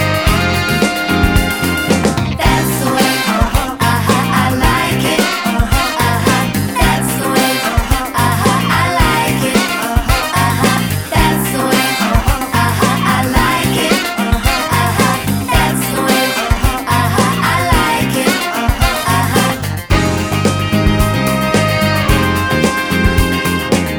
No Guitars Or Backing Vocals Disco 3:09 Buy £1.50